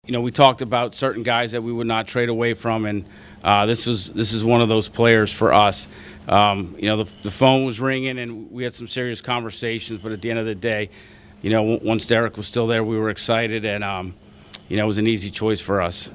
General manager Omar Khan says there was no doubt who the Steelers would take when their pick came up, even with Colorado quarterback Shedeur Sanders still on the board.